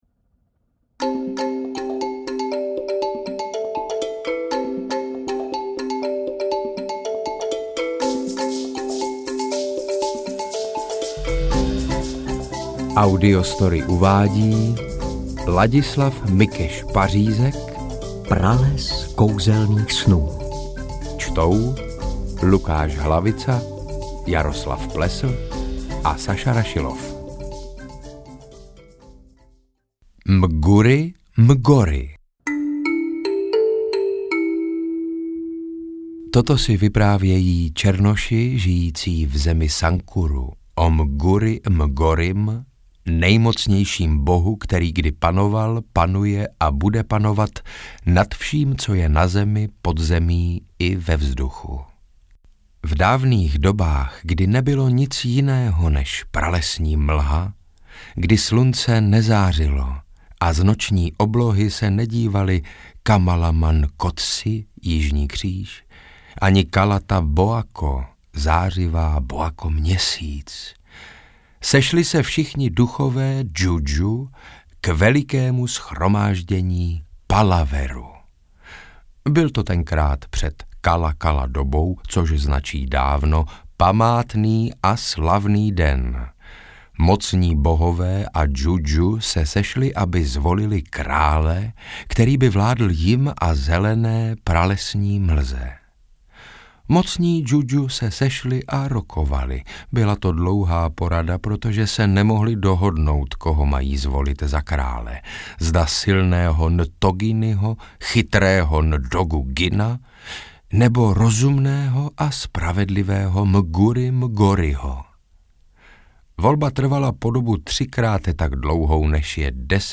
Interpreti:  Lukáš Hlavica, Jaroslav Plesl, Saša Rašilov
AudioKniha ke stažení, 21 x mp3, délka 4 hod. 37 min., velikost 280,1 MB, česky